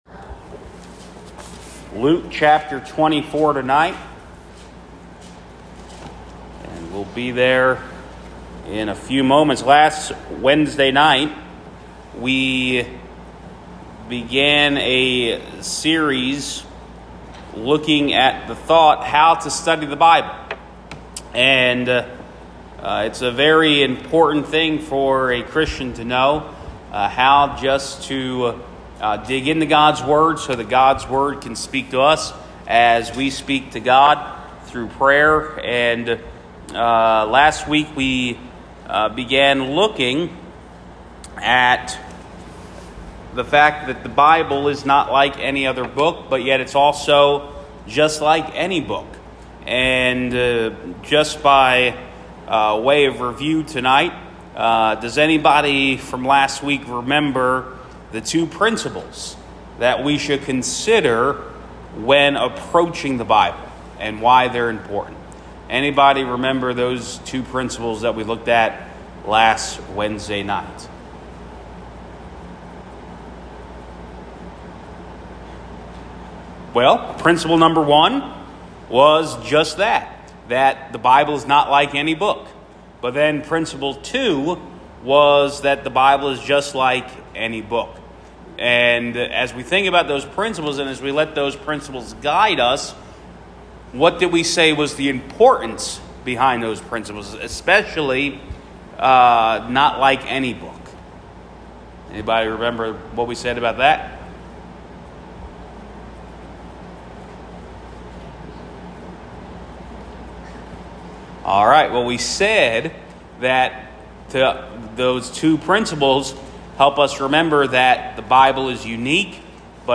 Sermons | First Baptist Church of Sayre, PA